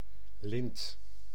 Ääntäminen
US : IPA : [rɪ.bən]